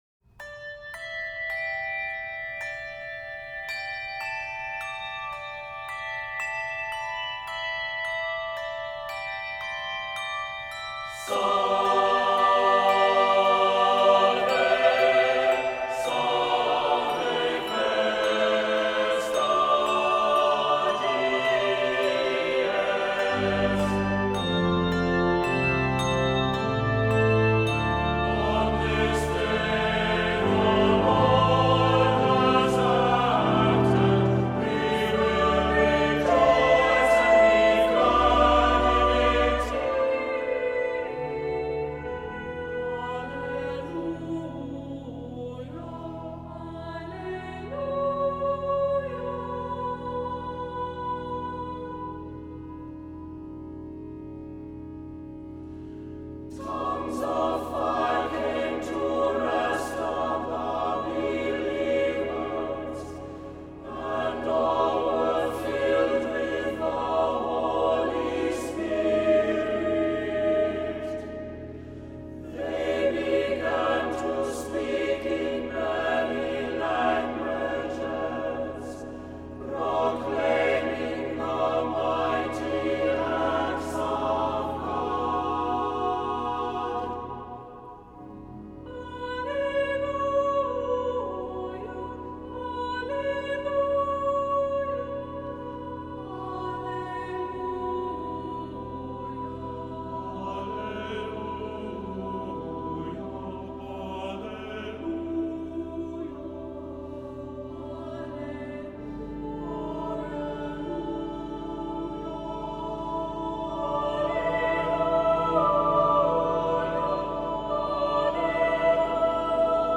Accompaniment:      Organ, Handbells;Percussion
Music Category:      Christian